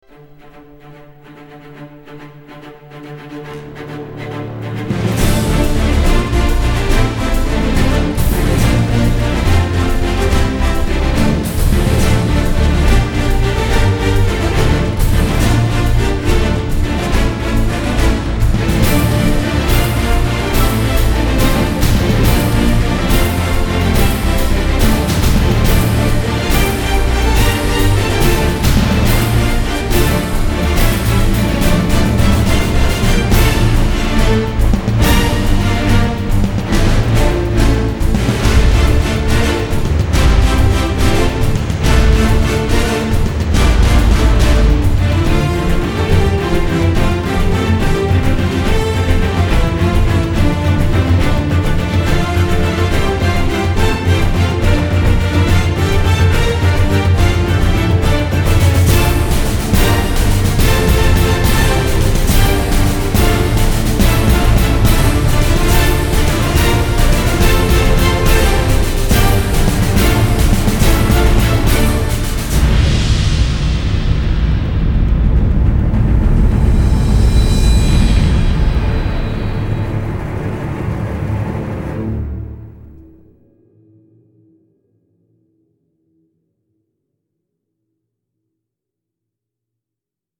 激昂，令人血液沸腾，相信你不会愿意错过它们……